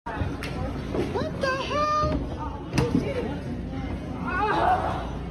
What The Hell? Laugh Sound Button - Free Download & Play
Memes Soundboard0 views